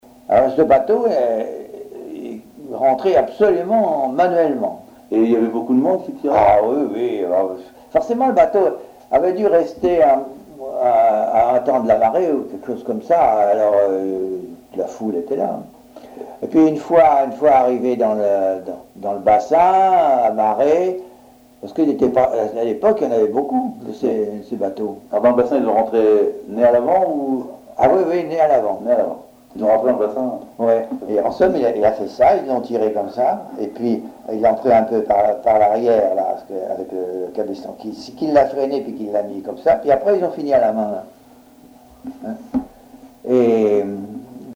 Témoignage